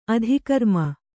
♪ adhikarma